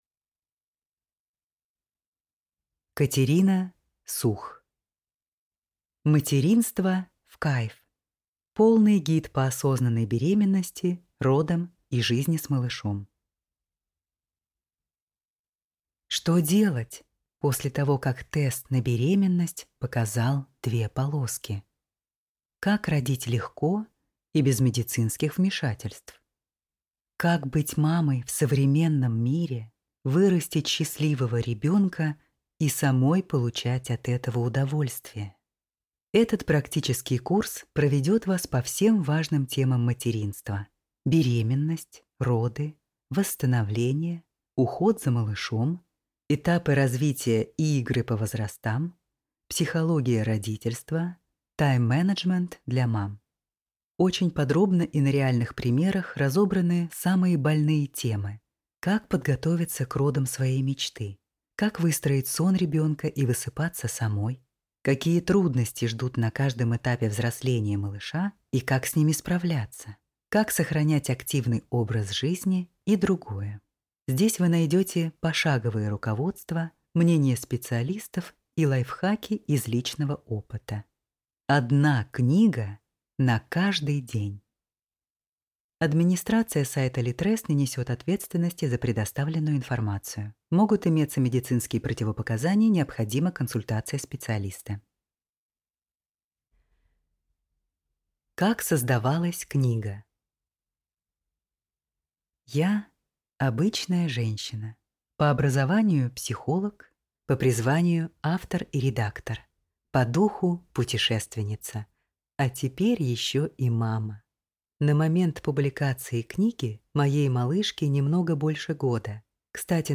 Аудиокнига Материнство в кайф. Полный гид по осознанной беременности, родам и жизни с малышом | Библиотека аудиокниг